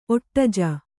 ♪ oṭṭaja